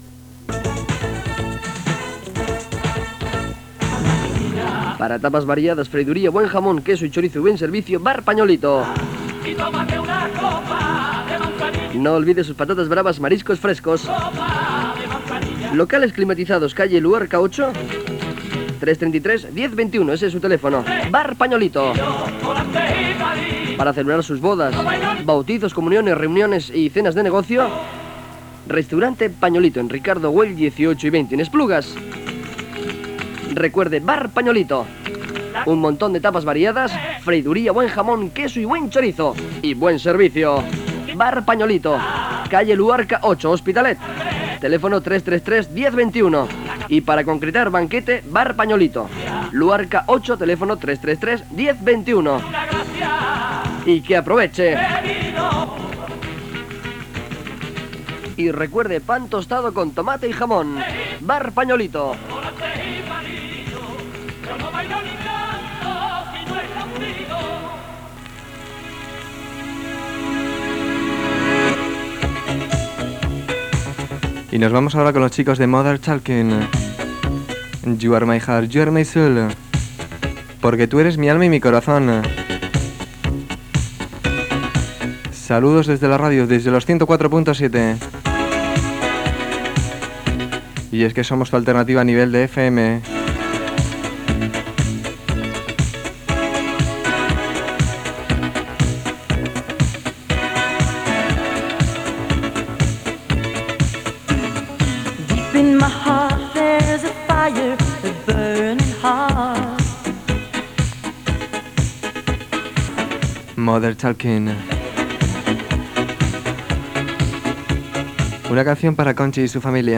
f567869d854f5d49bb3e124d53c5e1afa19880db.mp3 Títol Radio 40 Emissora Radio 40 Titularitat Tercer sector Tercer sector Comercial Descripció Publicitat, tema musical i identificació.